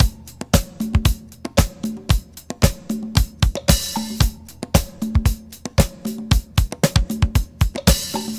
114 Bpm Drum Loop Sample A Key.wav
Free drum beat - kick tuned to the A note.
114-bpm-drum-loop-sample-a-key-6Zn.ogg